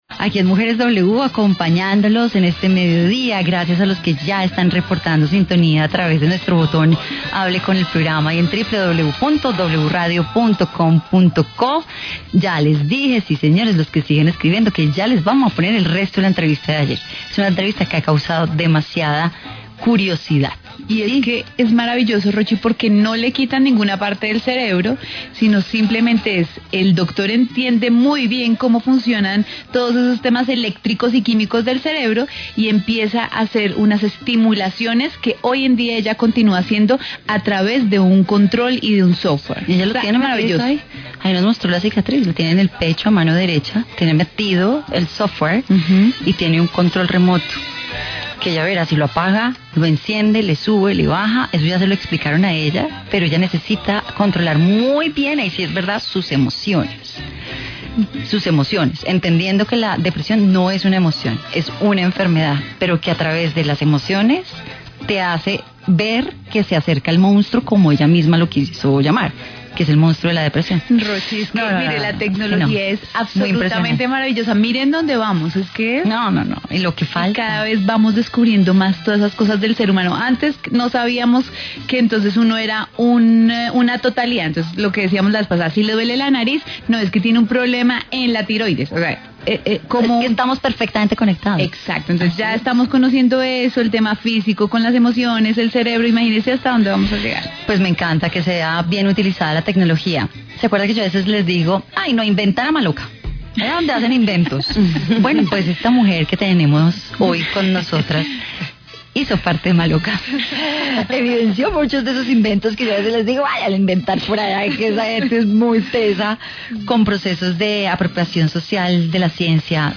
Entrevista en W Radio